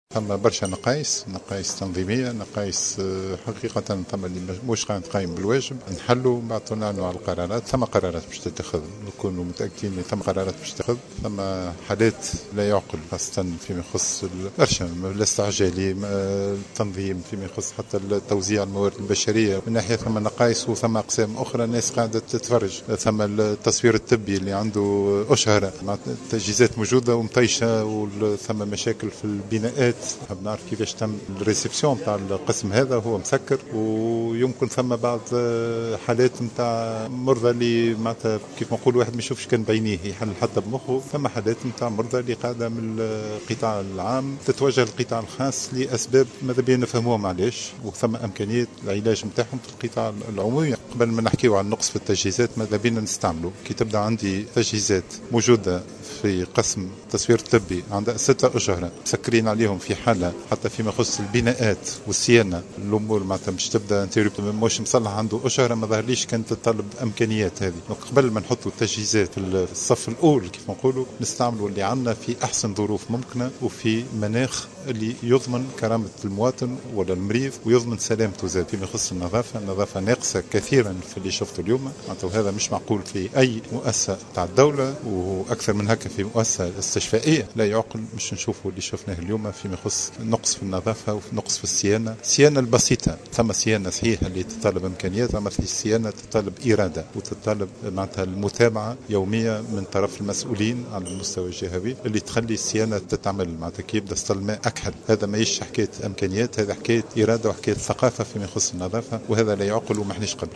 وأكد في تصريح لـمراسل "جوهرة أف أم" بالجهة أن هذه القرارات ستأخذ بعين الاعتبار الخلل الذي اكتشفه في المستشفى وأساسا على مستوى قسم الاستعجالي ومصلحة التصوير الطبي وسوء التنظيم الإداري بالنسبة لتوزيع الموارد البشرية.